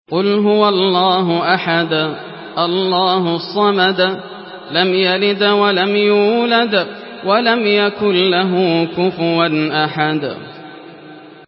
Surah Al-Ikhlas MP3 in the Voice of Yasser Al Dosari in Hafs Narration
Murattal